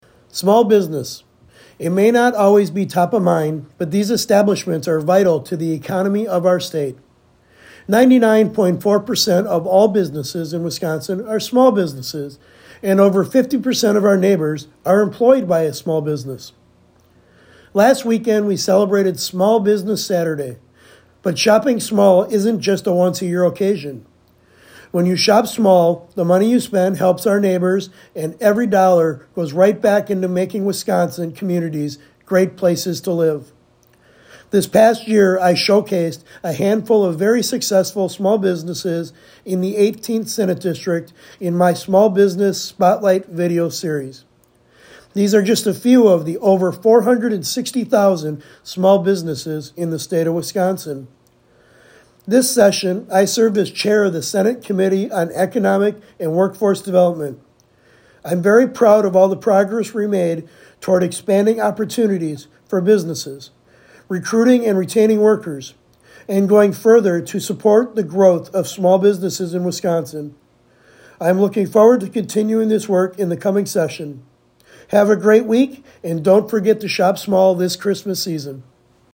Weekly GOP radio address: Sen. Feyen tells listeners to shop small this Christmas season - WisPolitics